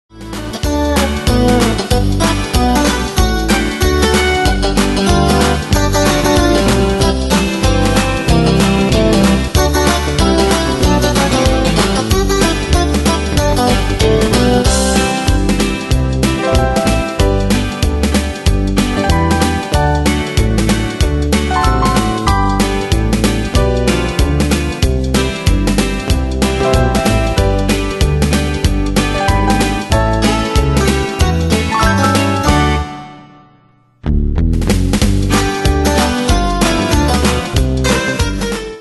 Style: Country Année/Year: 1998 Tempo: 95 Durée/Time: 2.24
Danse/Dance: TwoSteps Cat Id.
Pro Backing Tracks